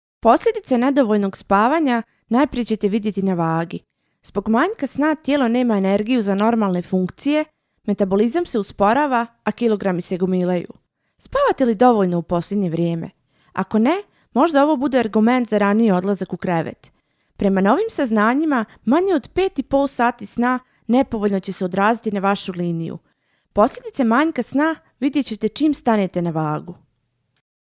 Boşnakca Seslendirme
Kadın Ses